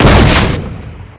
shot1.ogg